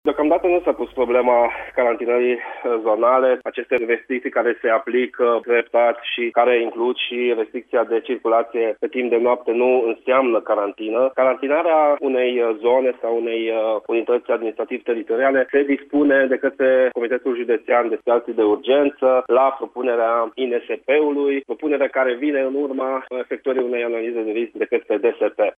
Precizarea a fost făcută la Radio Timișoara de subprefectul Andrei Molnar.
Andrei-Molnar-1.mp3